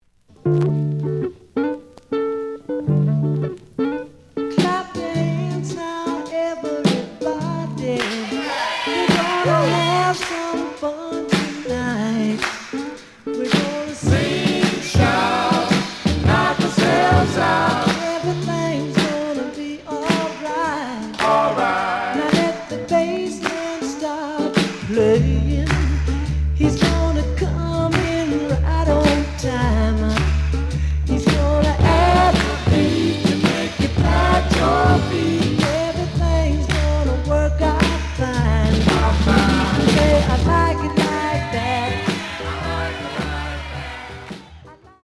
The audio sample is recorded from the actual item.
●Genre: Soul, 70's Soul
Slight noise on beginning of A side, but almost good.)